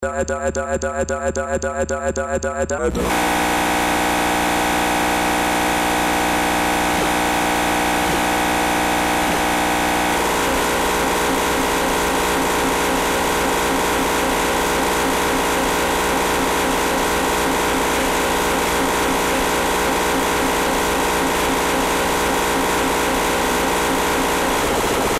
an output melodic and abrasive to the extreme.